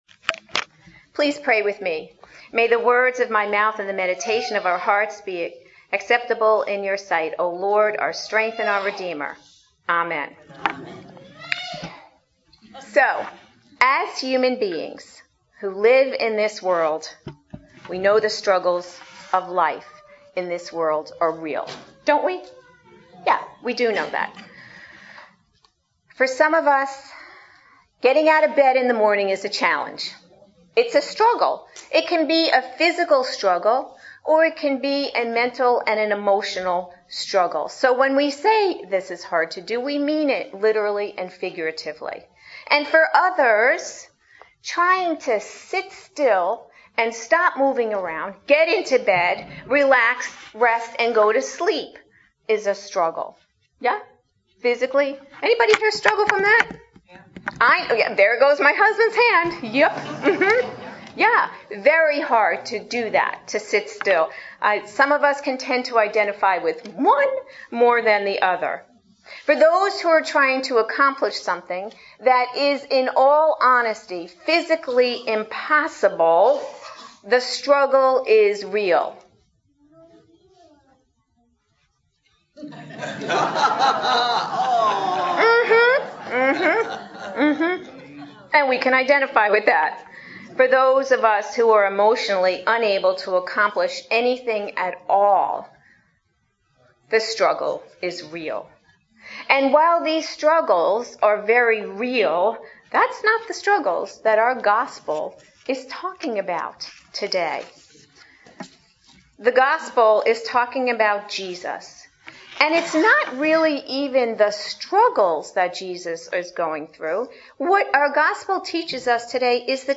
Series: Adult Sermons